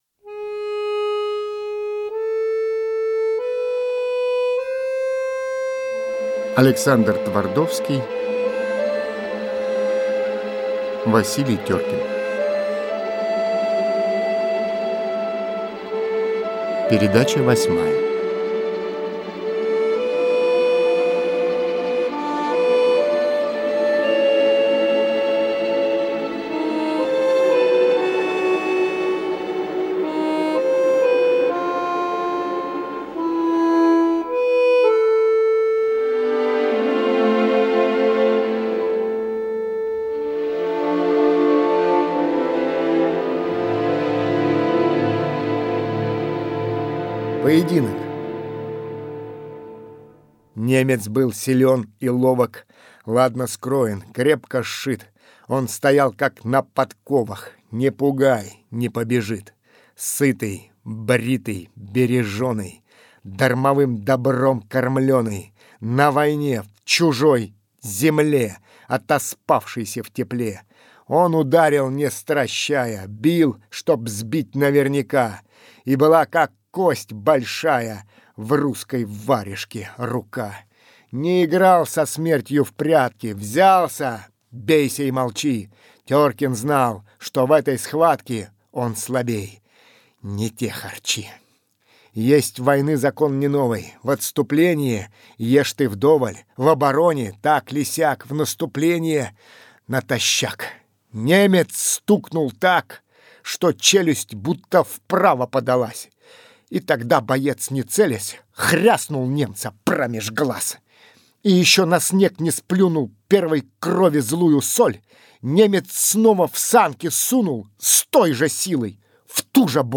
Все программы - Литературные чтения